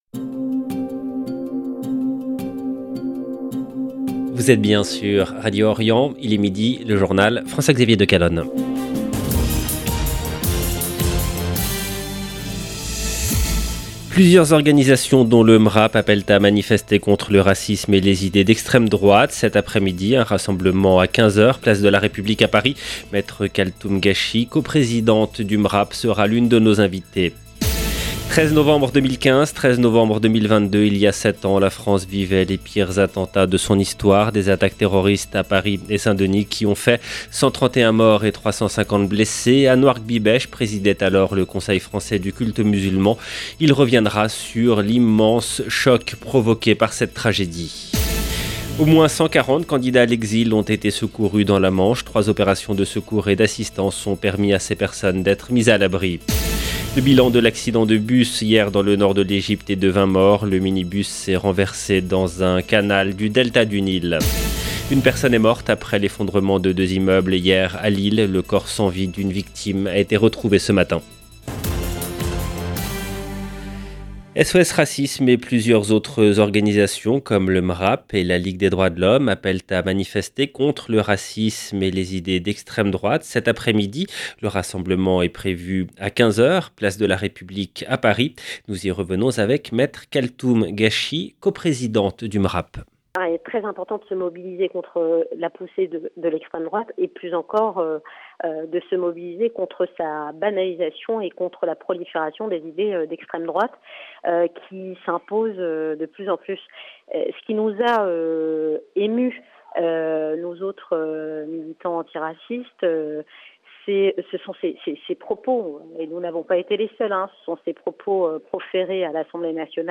EDITION DU JOURNAL DE 12H EN LANGUE FRANCAISE DU 13/11/2022